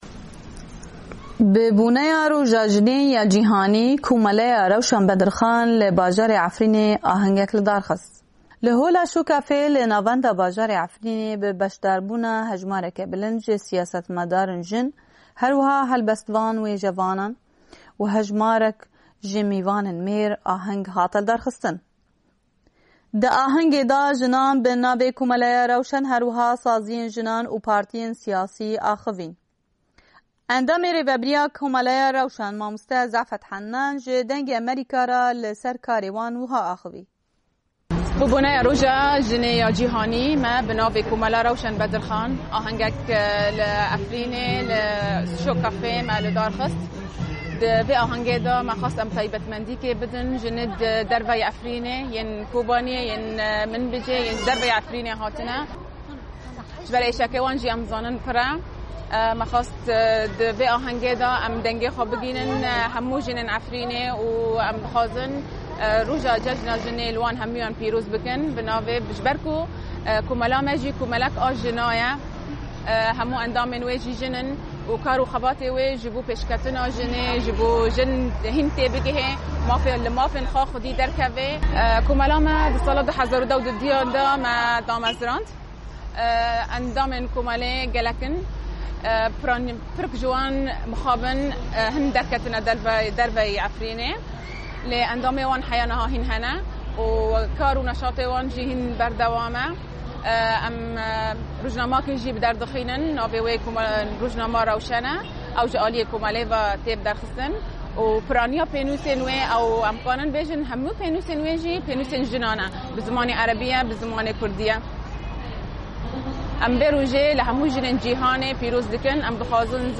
Ahenga Jinan li Efrînê
Di ahengê da nûnerên komeleyê û sazîyên jinan û hin partîyên sîyasî axifîn, herwiha hebestvanên jin helbestên xwe pêşkêş kirin, şagirtên Peymangeha Vîyan Amara jî şanoyek dîyarî jinan kirin.